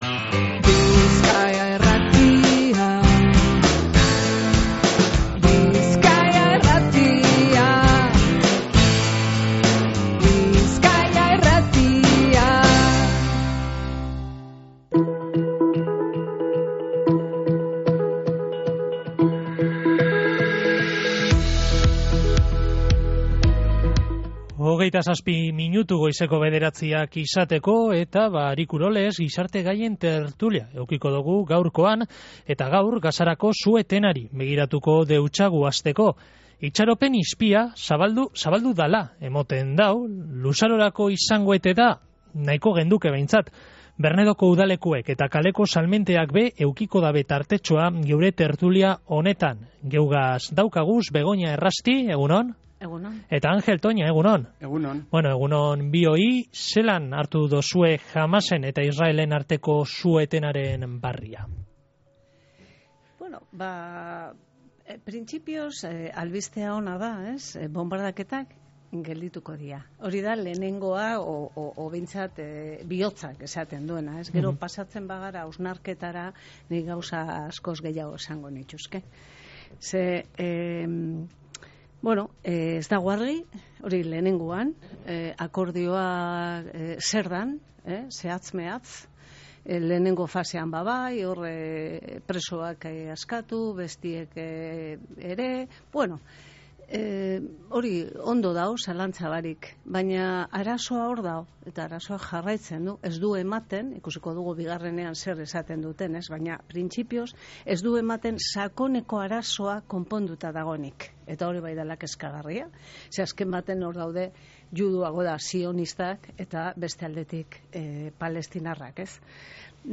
GIZARTE-GAIEN-TERTULIA.mp3